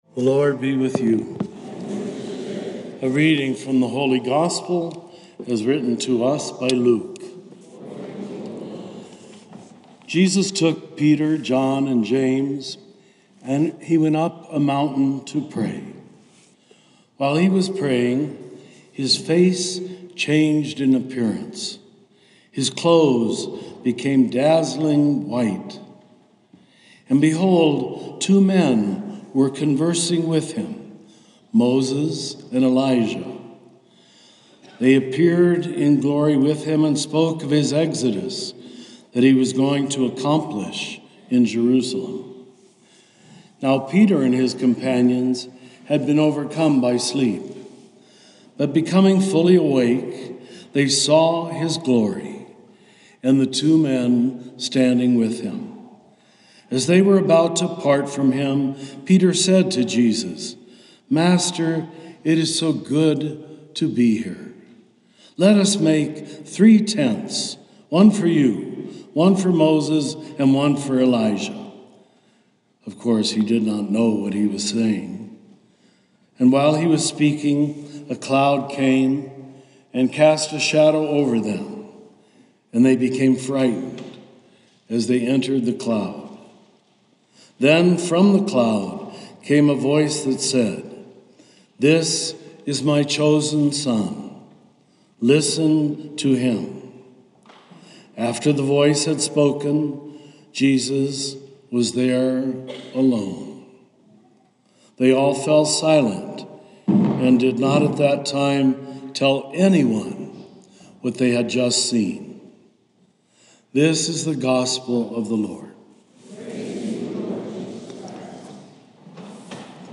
Homilies with Richard Rohr